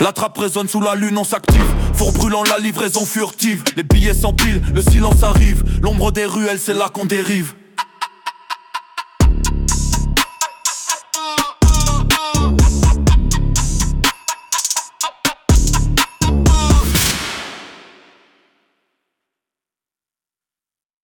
#9 — rimes embrassées (ABBA), – Trap Rap #04 Chargement de l’onde…
rimes-embrassees-ABBA-Trap-Rap-04.mp3